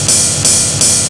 RIDE LOOP1-L.wav